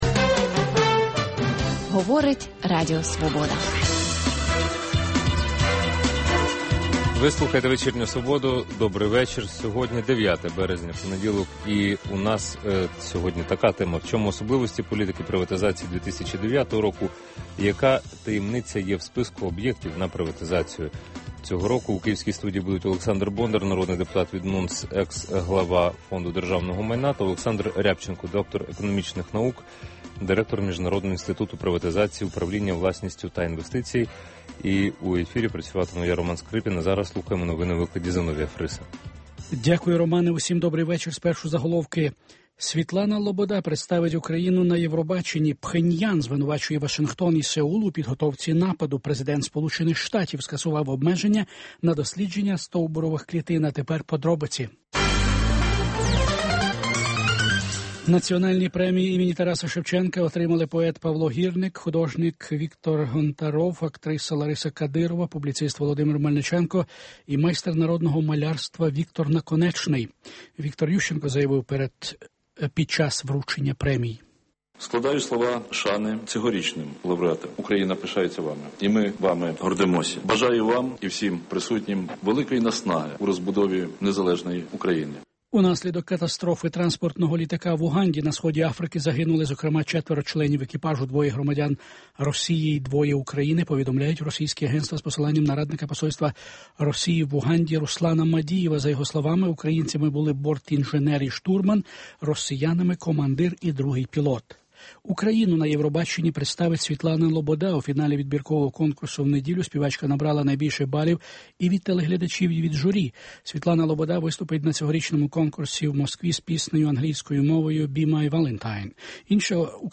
Інтелектуальна дуель у прямому ефірі. Дискусія про головну подію дня, що добігає кінця.